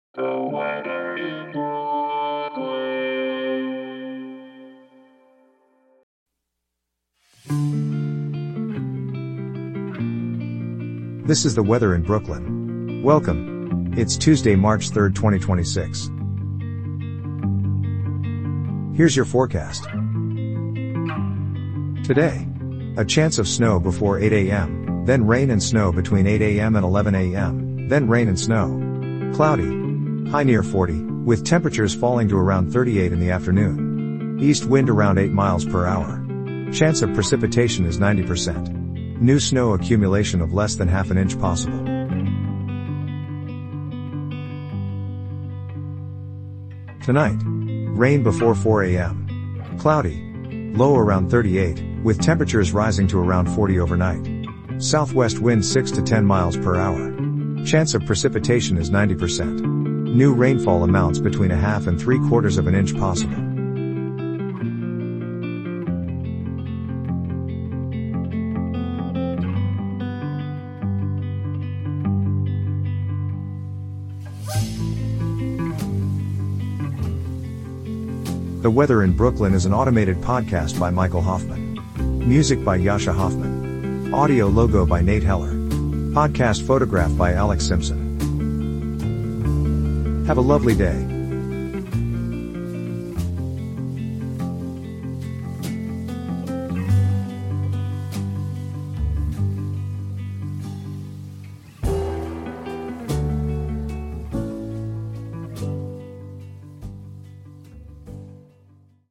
An automated podcast bringing you your daily weather forecast for Brooklyn, NY.